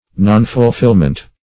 Nonfulfillment \Non`ful*fill"ment\, n.